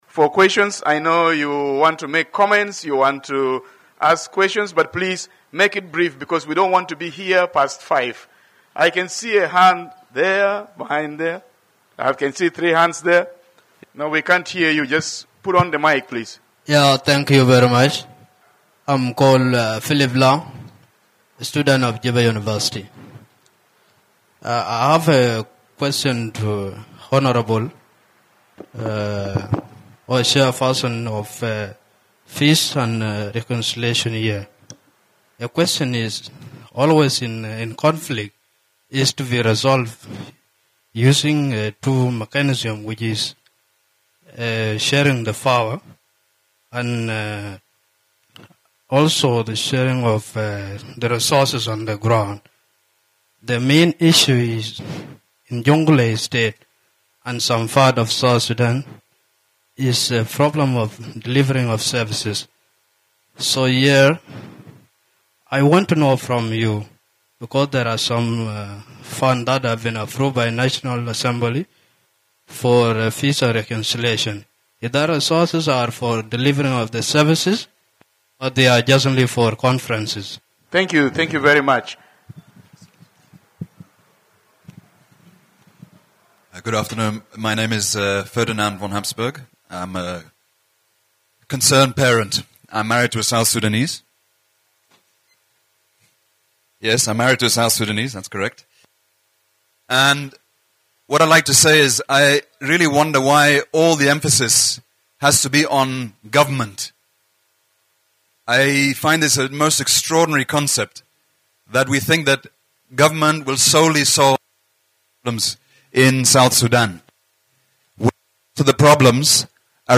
More than 100 people attended a lively town hall meeting hosted by Voice of America in Juba.